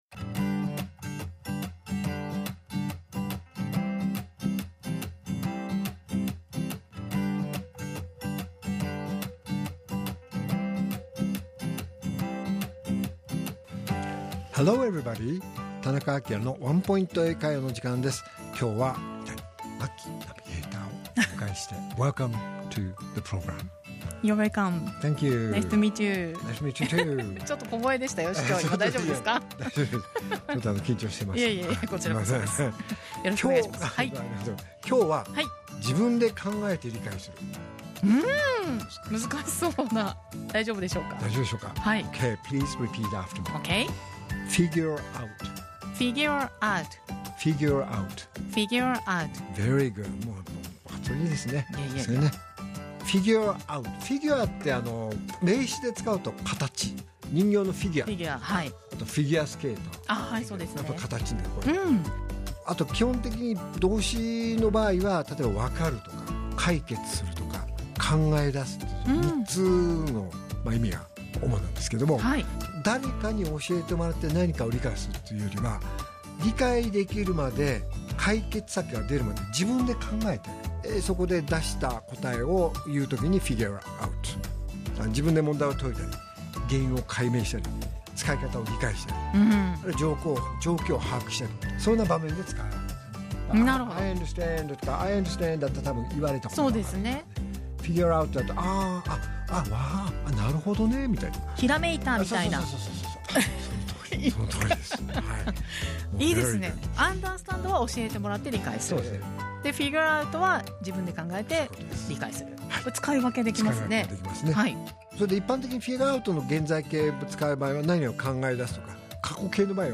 R7.12 AKILA市長のワンポイント英会話